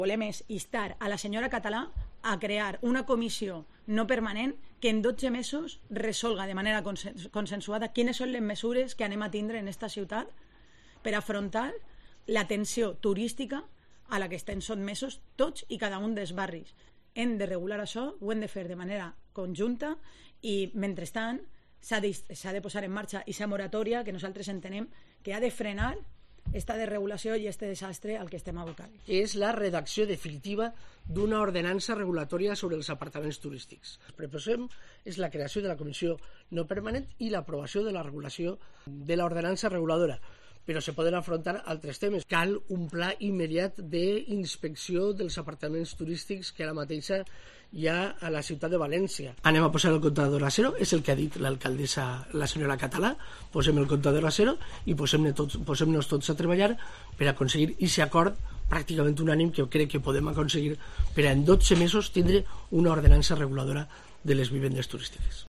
Declaraciones Papi Robles y Ferran Puchades de Compromís